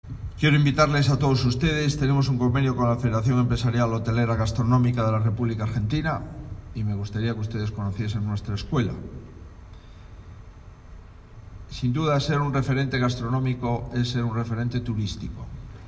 El presidente de la Xunta asistió hoy al acto de inauguración del Obradoiro de Sabores.